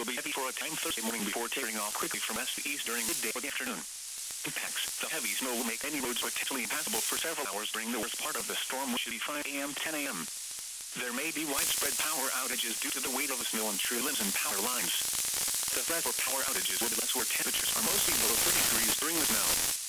Starting from the bottom and scanning upwards, the first signal I spotted was at 23.583 Mhz:
The recording interface works pretty well in GQRX, but some of the signals were pretty quiet, especially compared to the static that surrounds it. I’ve posted some recordings, unedited besides normalization processing to boost the quiet audio to audible levels:
weatherStationRecording.mp3